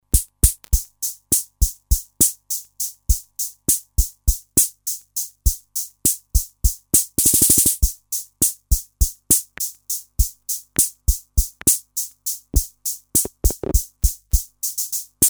Vintage analog drum machine with preset pattern rhythms and simple trigger pads
2-rhythms pattern
demo rock pattern